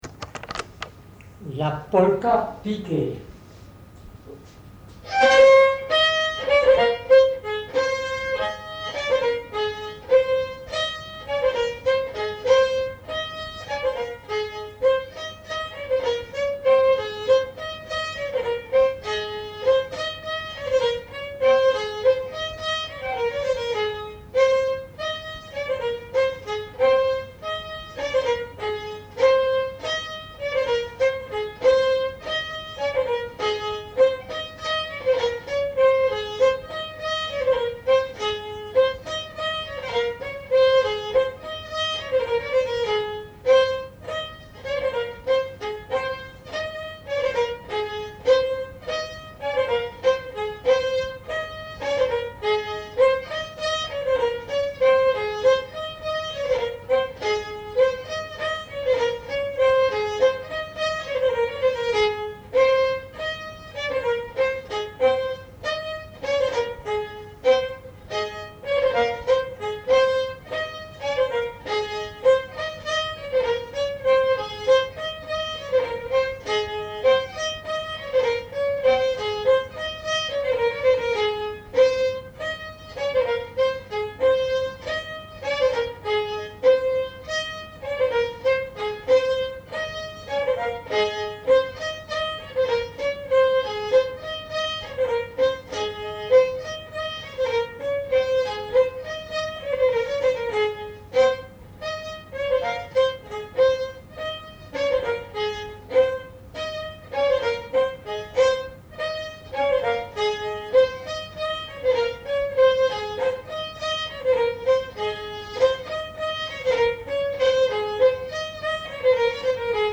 Genre : morceau instrumental
Instrument de musique : violon
Danse : polka piquée